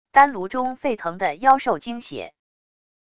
丹炉中沸腾的妖兽精血wav音频